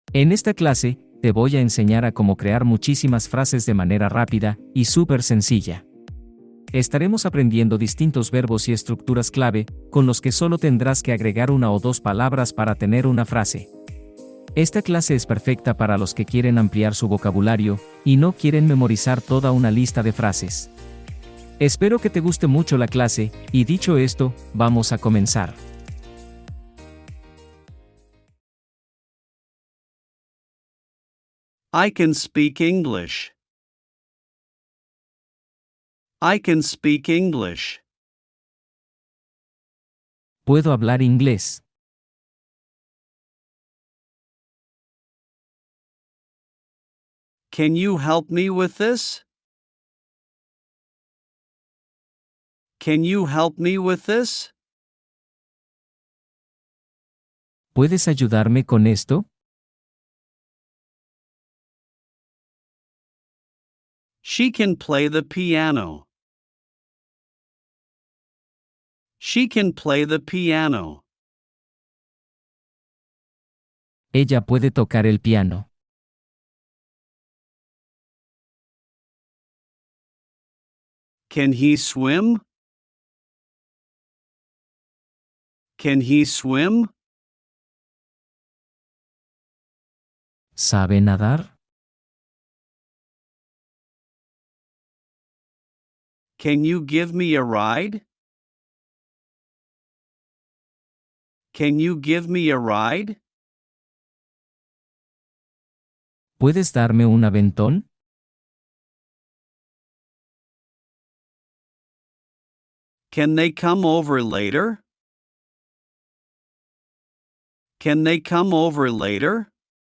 30 minutos de diálogos cortos para aprender inglés rápido